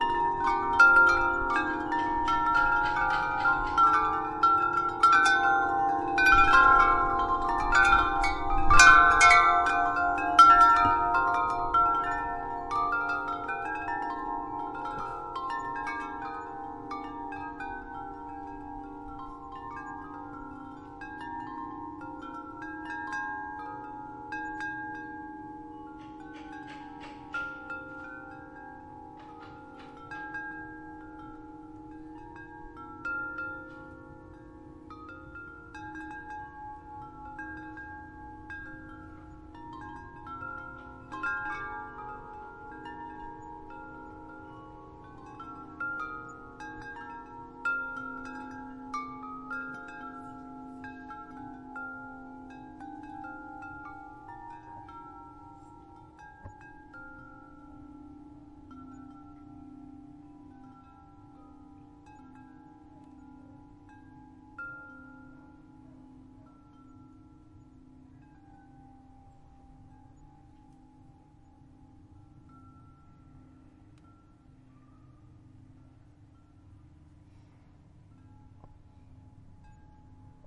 大钟声LR
描述：这是我家后院的大型风铃录音。我使用了带有中侧麦克风的Zoom H6。
Tag: 后院 编钟